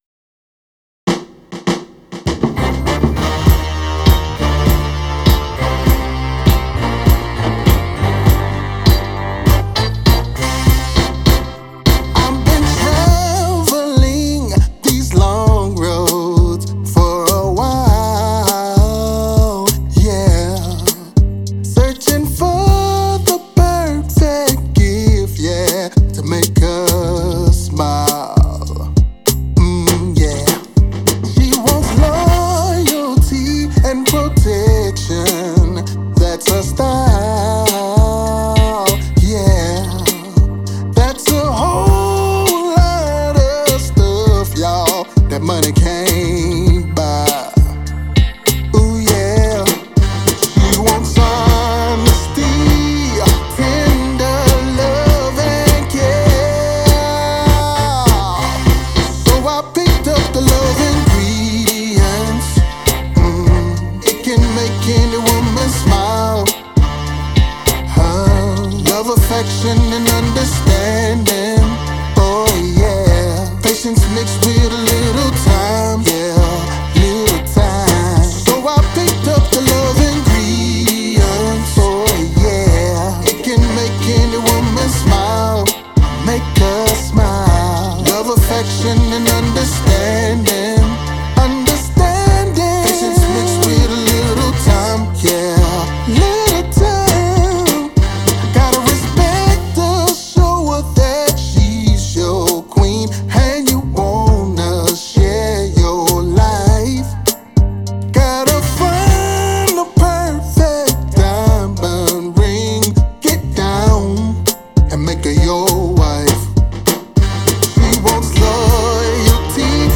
Southern Soul R&B single